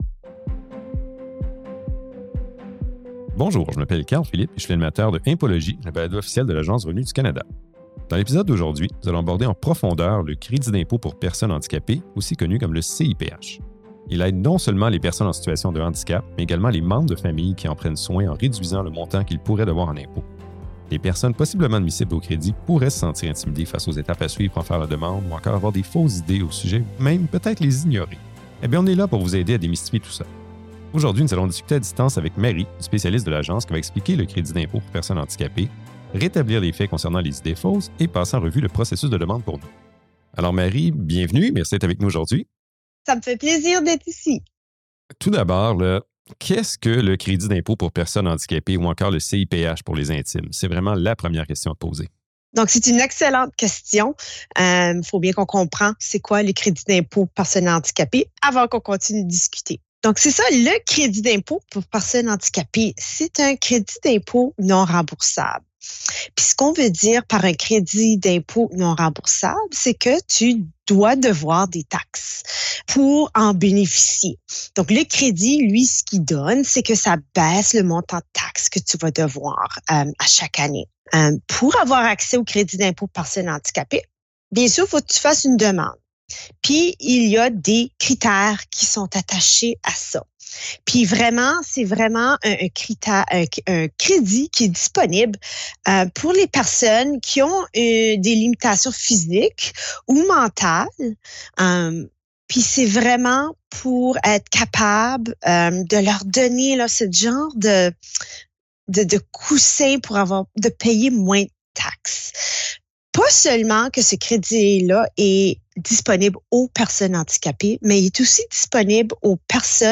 Dans cet épisode, nous discutons avec un expert de l’Agence sur le CIPH pour vous aider à le comprendre et à comprendre le processus de demande, et pour dissiper toute fausse idée.